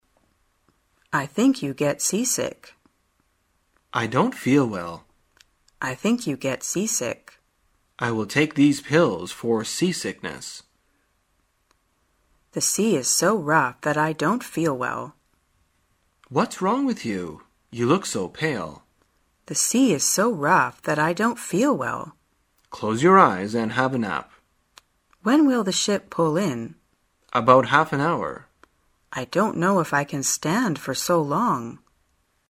在线英语听力室生活口语天天说 第132期:怎样谈论晕船的听力文件下载,《生活口语天天说》栏目将日常生活中最常用到的口语句型进行收集和重点讲解。真人发音配字幕帮助英语爱好者们练习听力并进行口语跟读。